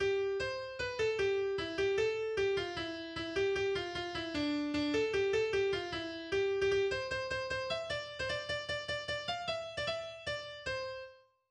schweizerisches Volkslied